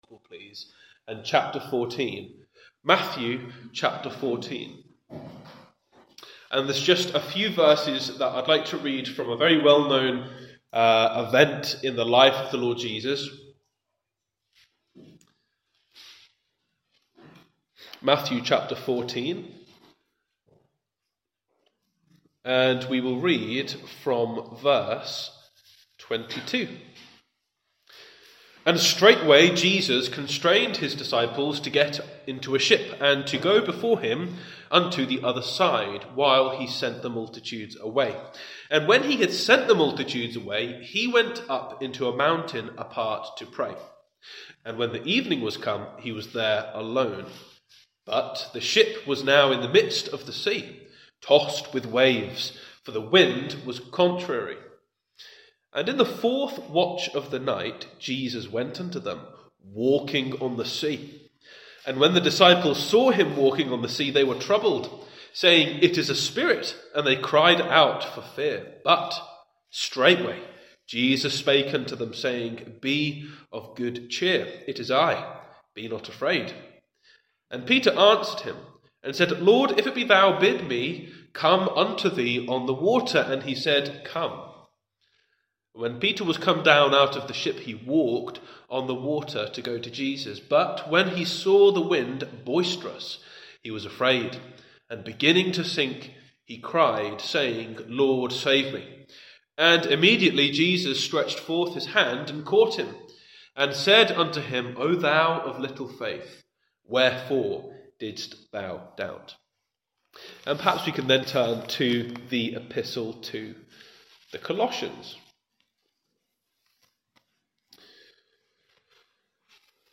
In this introductory sermon, ahead of our Bible Readings in Colossians, the context of the book is considered.